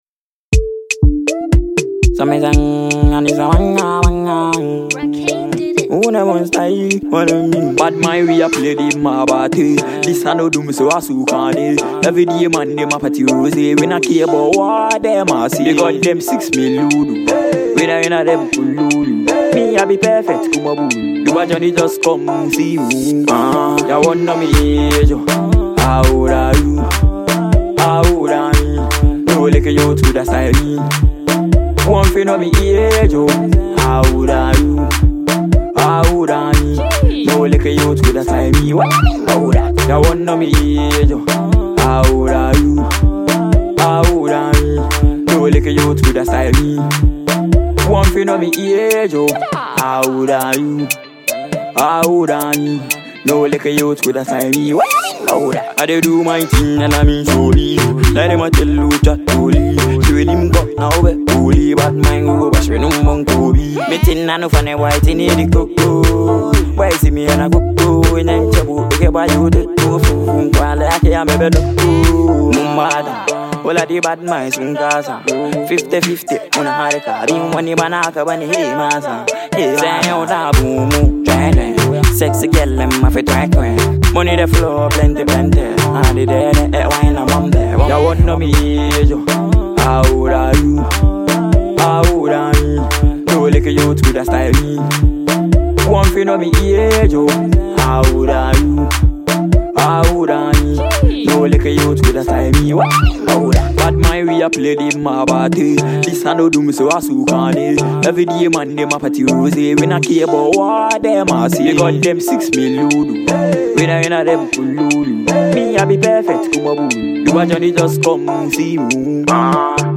impressive dancehall cruise